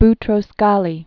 (btrōs-gälē), Boutros 1922-2016.